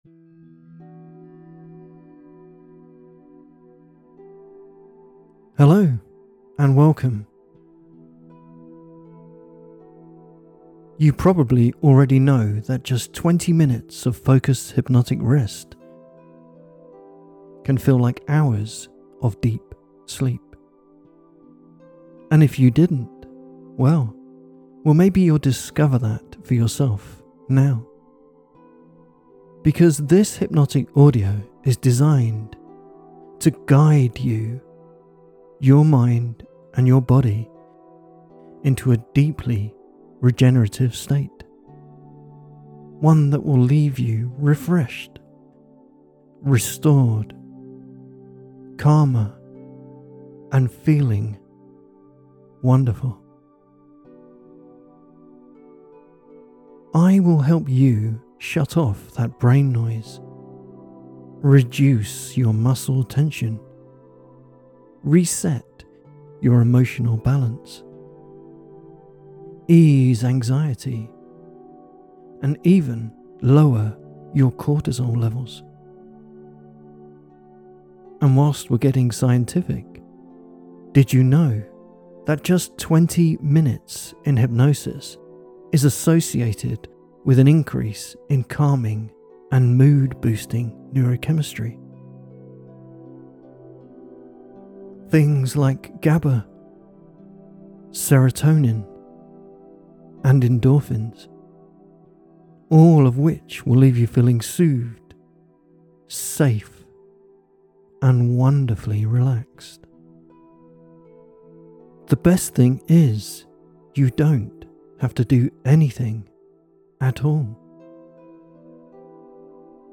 So let me guide you, your mind, and your body into a deeply regenerative state, one that will leave you refreshed, restored, calmer and feeling great.